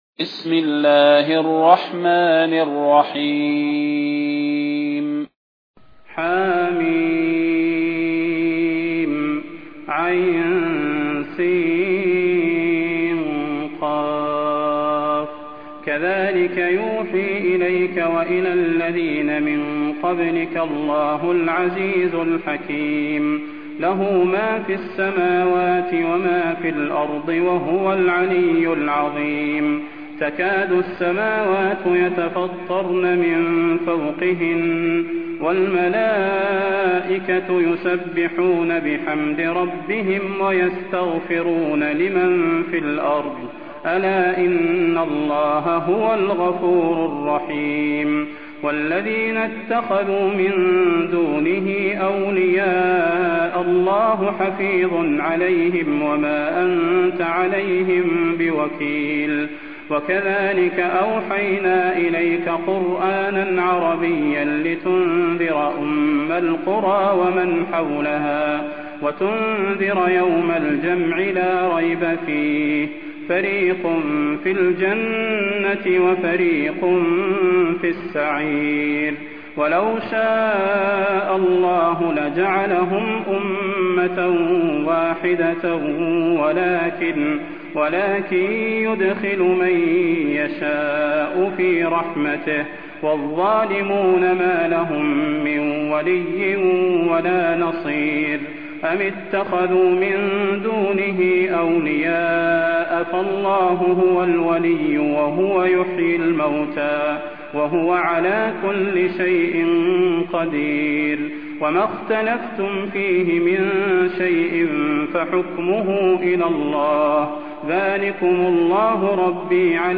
المكان: المسجد النبوي الشيخ: فضيلة الشيخ د. صلاح بن محمد البدير فضيلة الشيخ د. صلاح بن محمد البدير الشورى